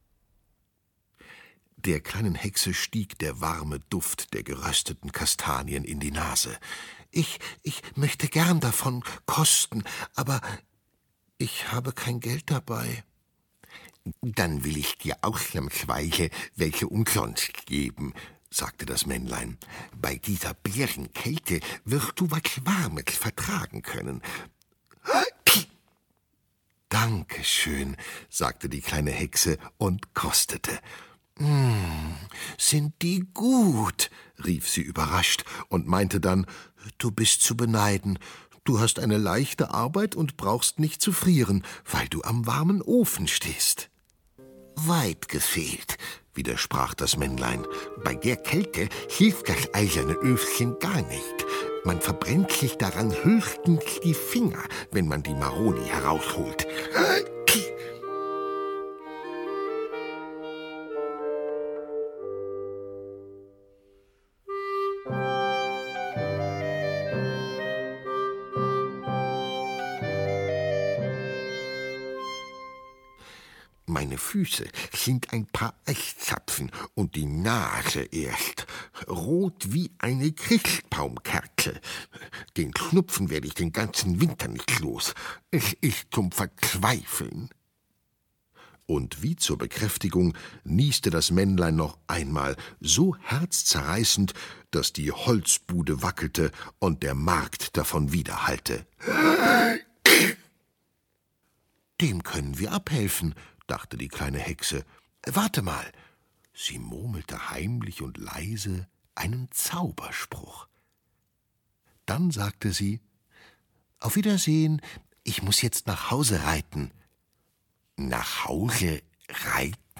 Hörbuch: Die schönsten Familienkonzerte.